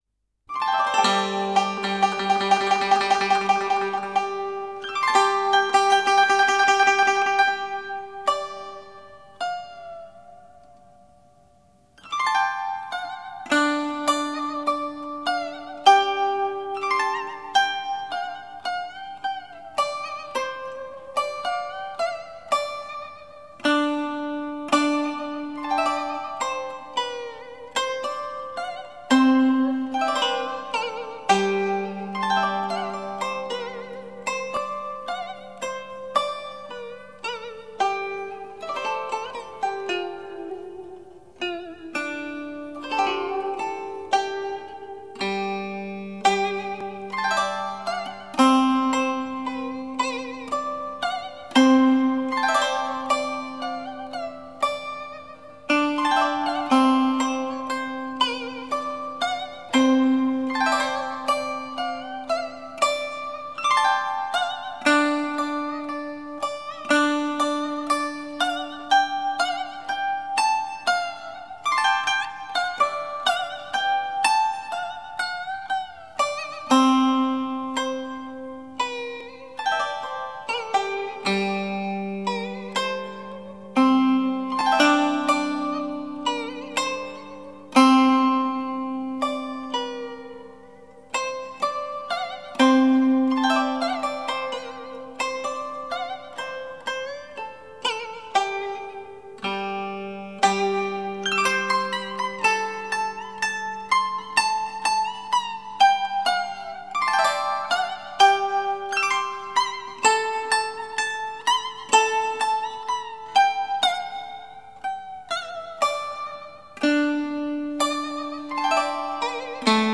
中国古筝经典